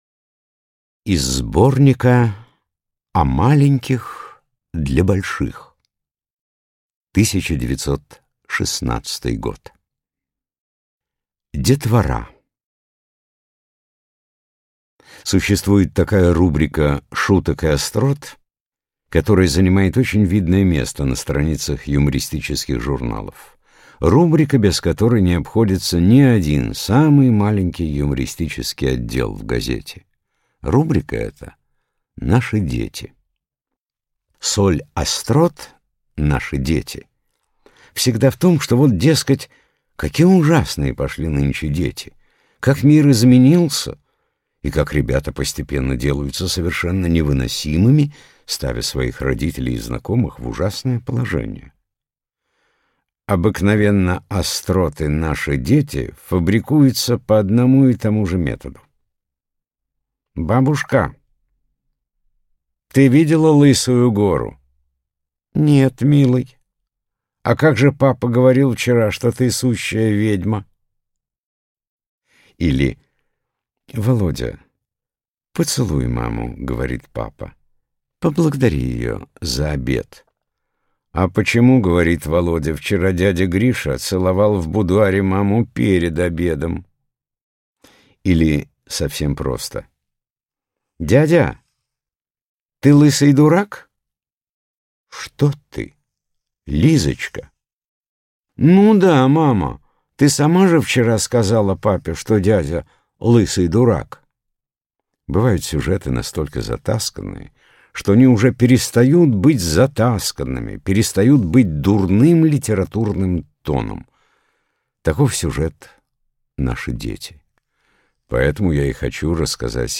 Аудиокнига «Мой сосед по кровати» и другие юмористические рассказы | Библиотека аудиокниг